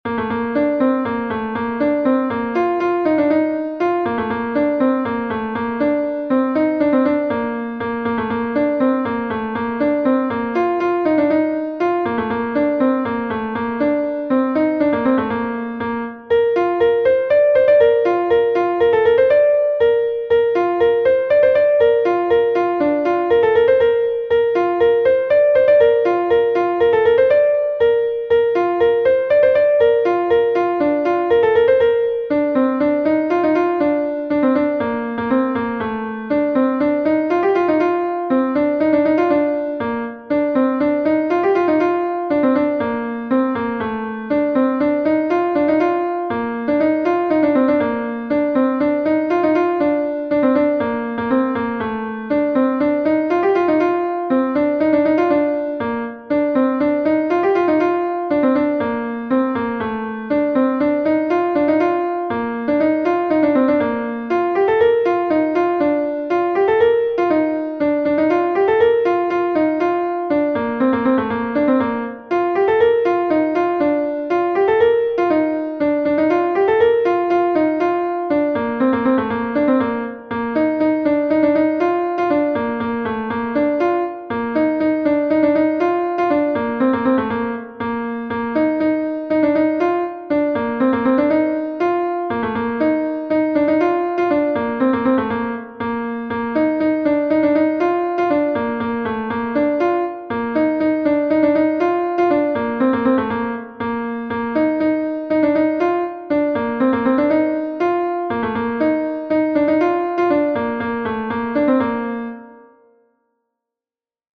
Gavotenn Sant-Tudal V est un Gavotte de Bretagne